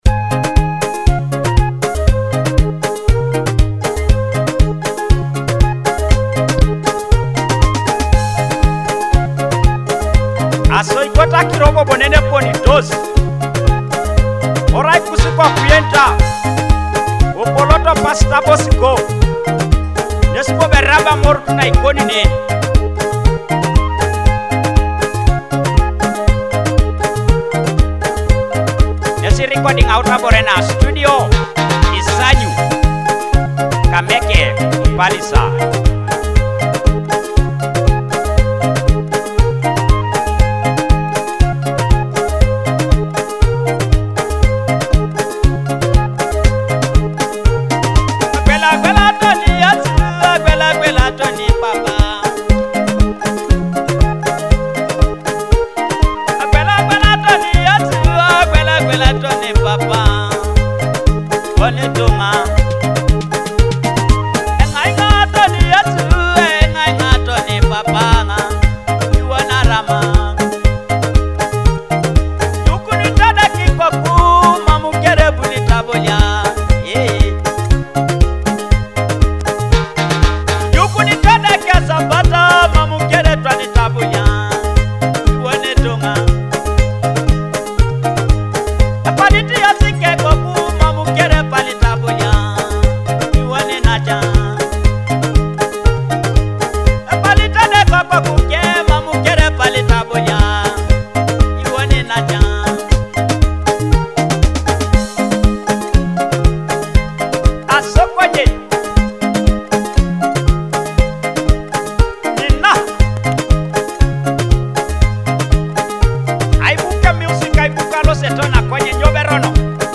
joyful Ateso cultural and traditional rhythms
Akogo (thumb piano)
Adungu (arched harp)